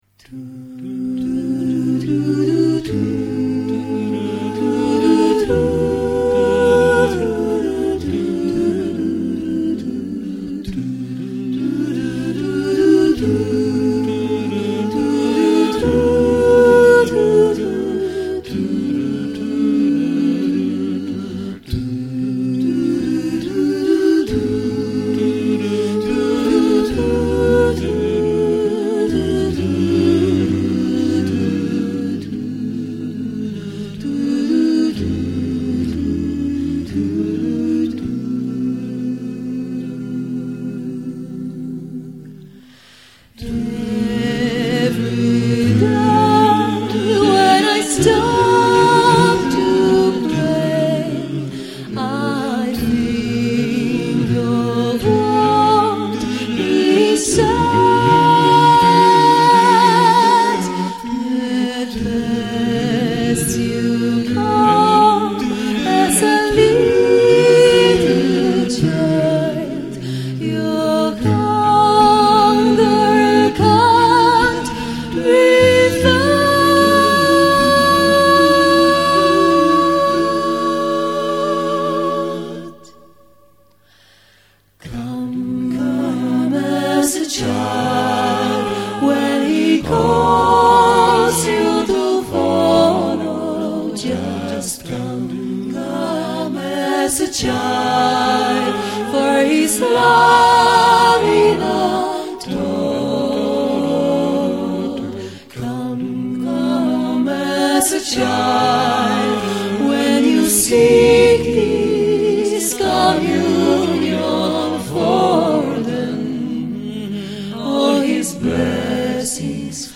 światowej sławy zespołu wokalnego.
Obecnie grupa liczy sześć osób.